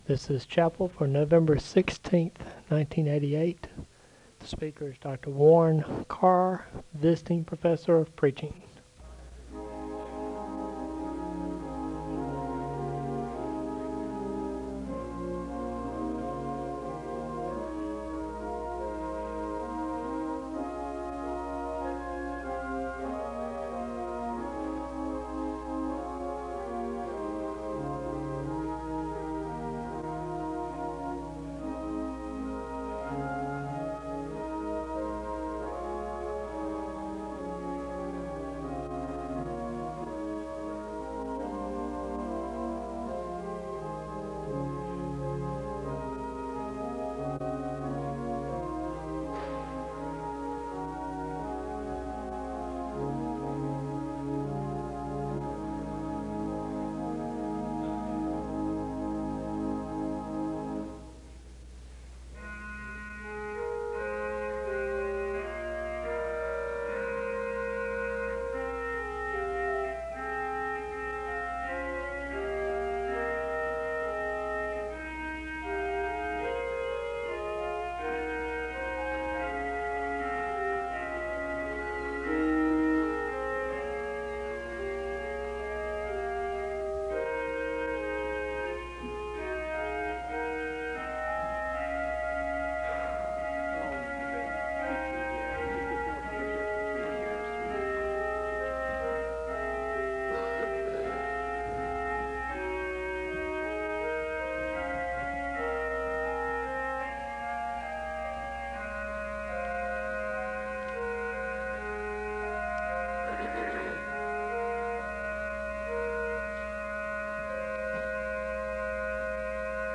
A hymn is played followed by a word of prayer (0:09-4:48). A hymn is played (cut) (4:49-4:54).
A prayer list is given, followed by a word of prayer (4:55-9:04).
The choir sings a song of worship (10:51-13:43).
The service closes with a word of prayer (33:31-34:00).